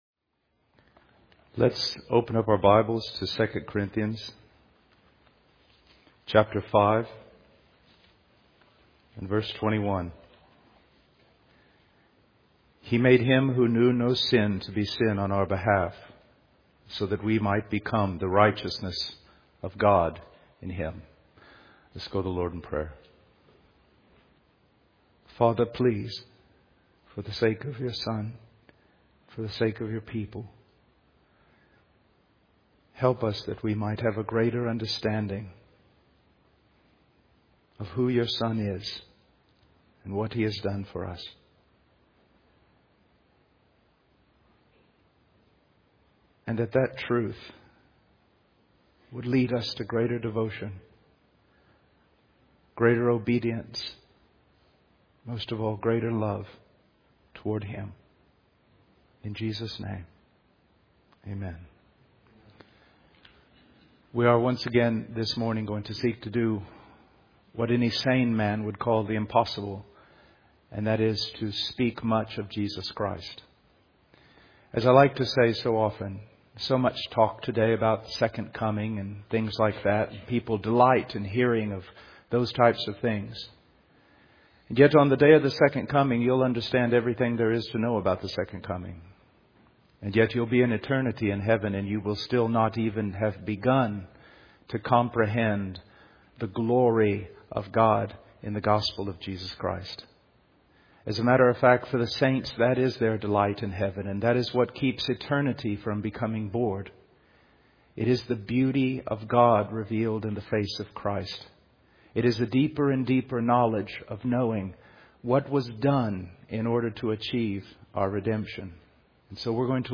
In this sermon, the speaker reflects on the anticipation of the second part of the story of God's plan for salvation. He describes the scene of Jesus, the Son of God, being crucified on a tree, emphasizing the sacrifice and the love of God for humanity.